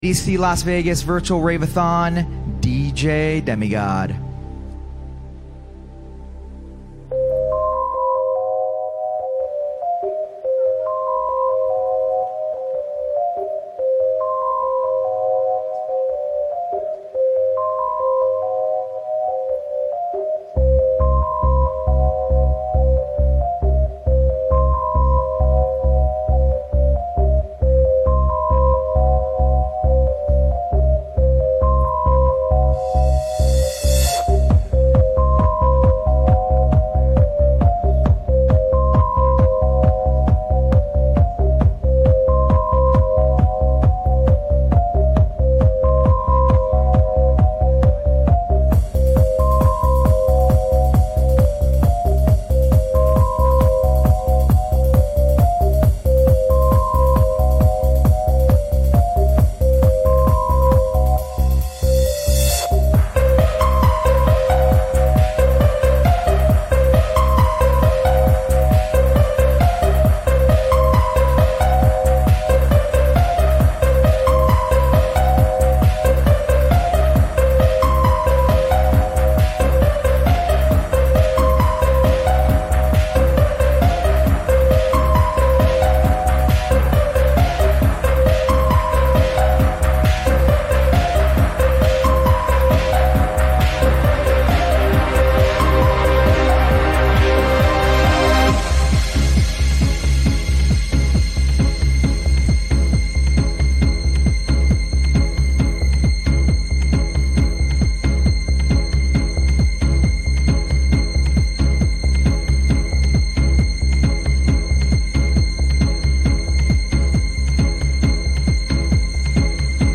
DJ Mixes and Radio
Quarantine Livestreams Genre: House